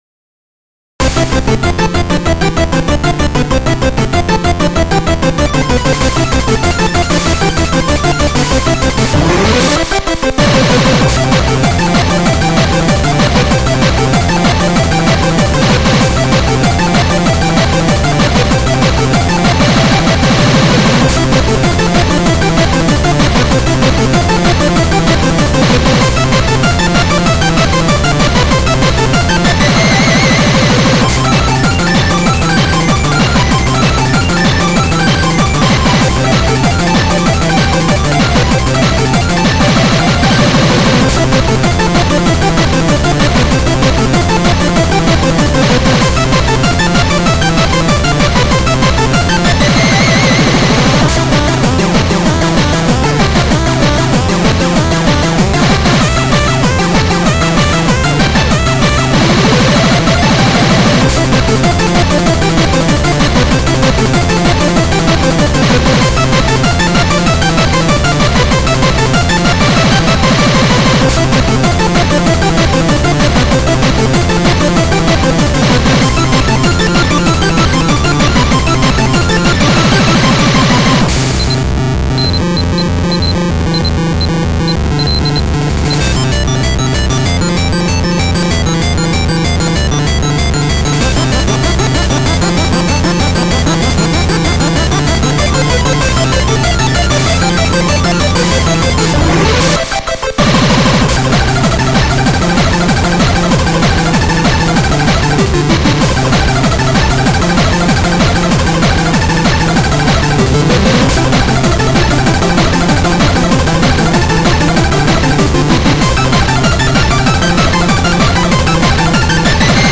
data/localtracks/Japanese/J-Core